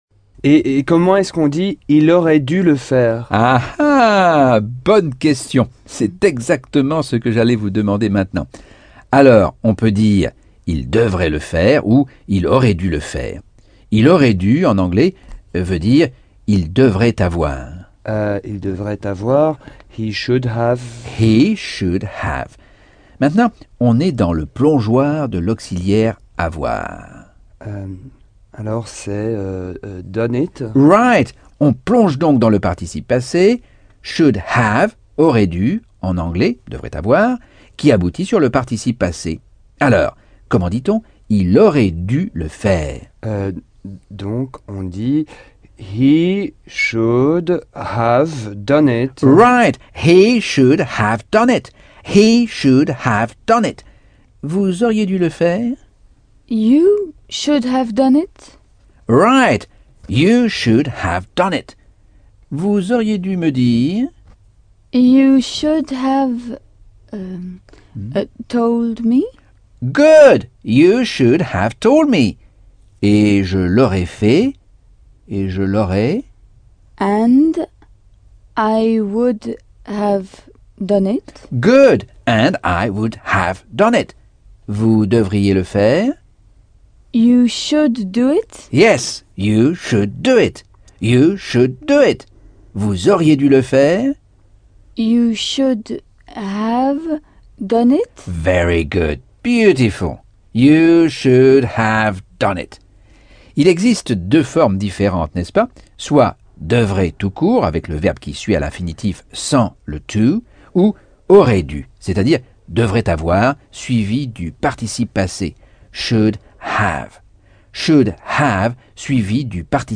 Leçon 8 - Cours audio Anglais par Michel Thomas - Chapitre 9